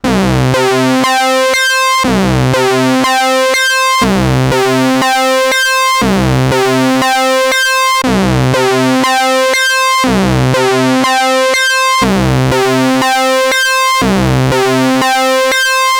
korg_collection_arp_odyssey_sync.mp3